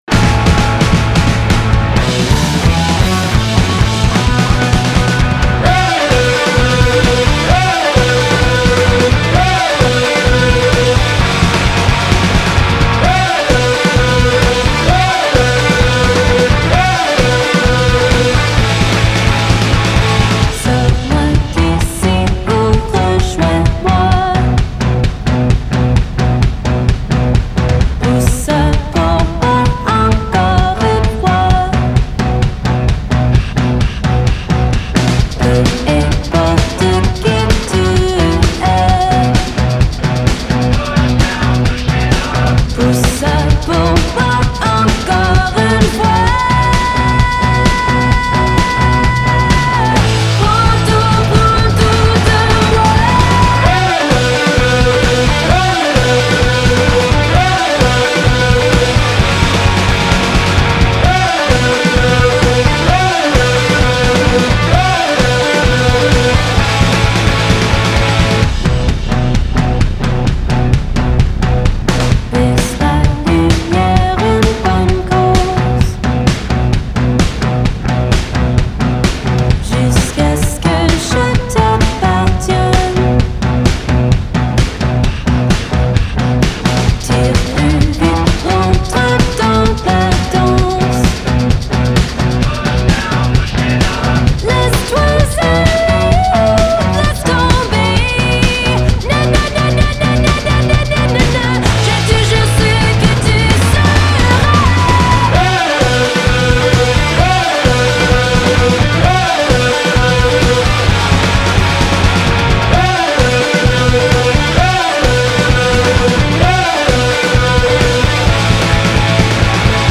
French modern rock.